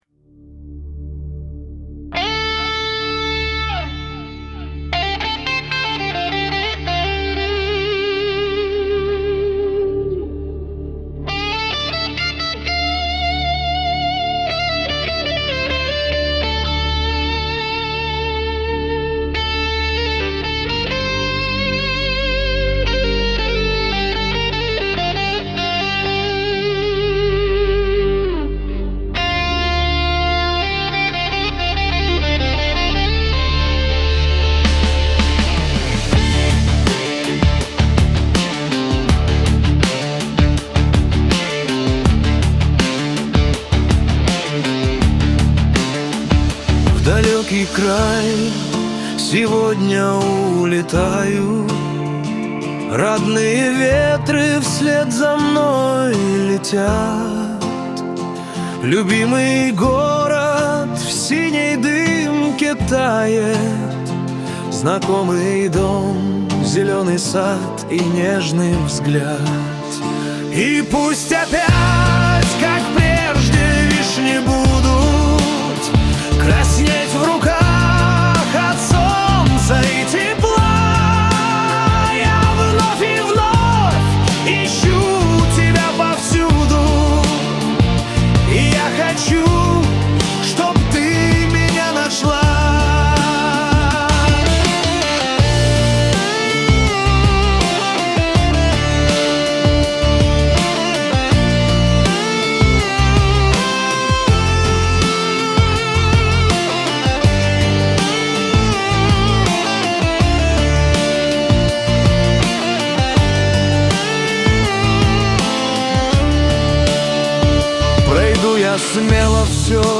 Партии гитары небрежные, так, по-быстрому накидал для примера.